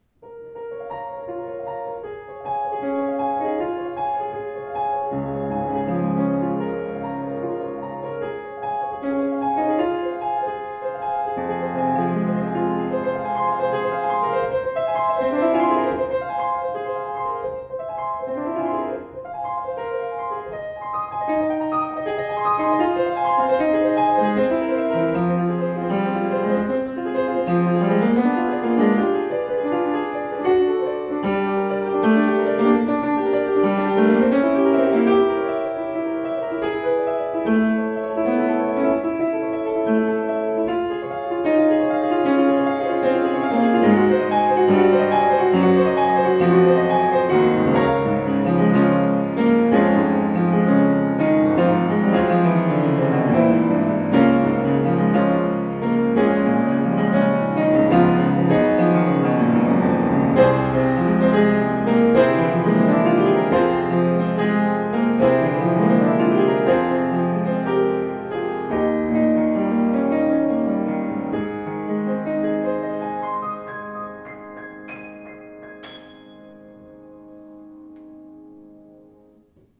Klavier Yamaha U3 schwarz
demnächst wieder verfügbar: U3 mit vollem, konzertantem Klang in schwarz poliert